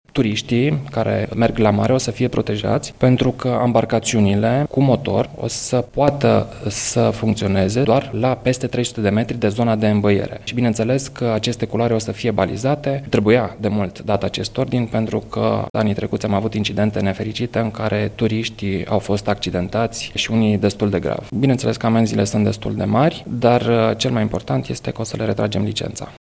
Turiştii aflaţi pe litoral au motive să se simtă mai în siguranţă – skijet-urile vor fi interzise în zonele unde se înoată. Ministrul Turismului, Bogdan Trif, precizează într-un ordin pe această temă, că ambarcaţiunile cu motor au acces doar la o distanţă precis reglementată faţă de plajă: